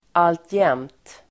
Ladda ner uttalet
alltjämt adverb, constantly , still Uttal: [altj'em:t] Synonymer: ständigt Definition: fortfarande Exempel: rymlingen är alltjämt på fri fot (the fugitive is still at large) still adverb, ännu , fortfarande , alltjämt